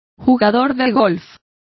Complete with pronunciation of the translation of golfers.